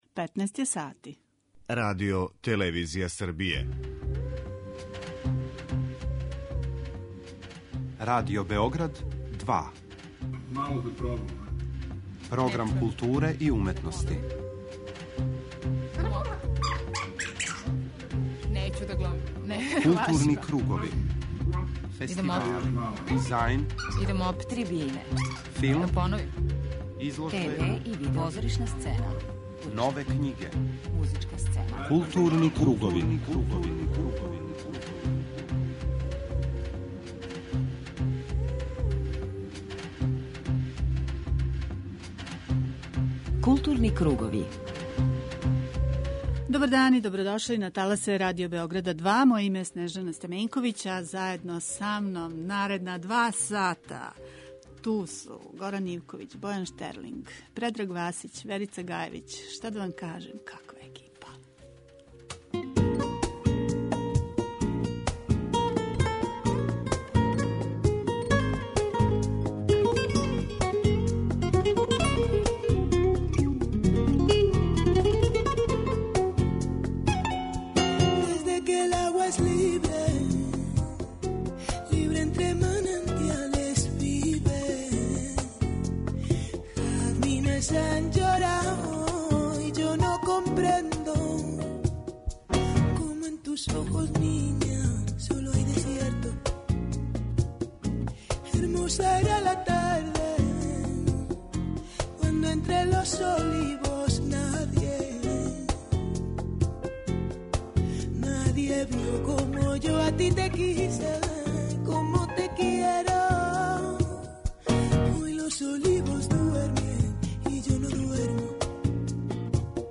преузми : 40.79 MB Културни кругови Autor: Група аутора Централна културно-уметничка емисија Радио Београда 2.